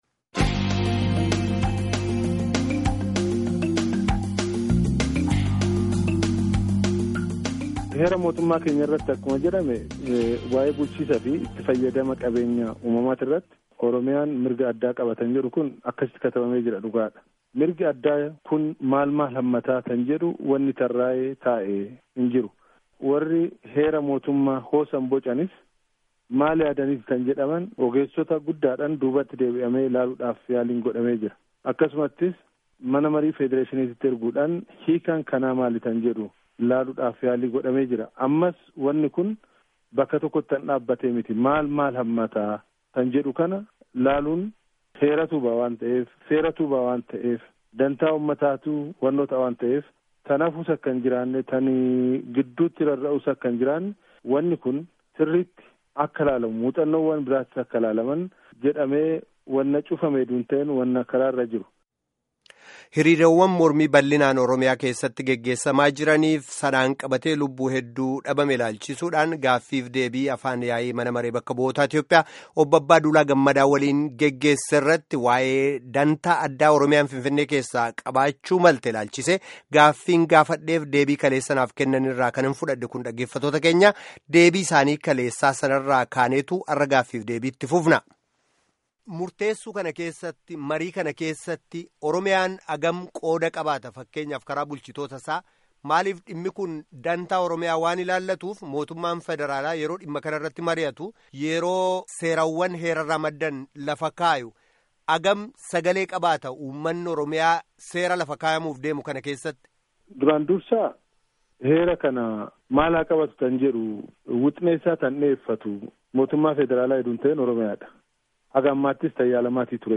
Gaaffii fi Deebii Abbaa Duulaa Gammadaa waliin geggeeffame kutaa 2ffaa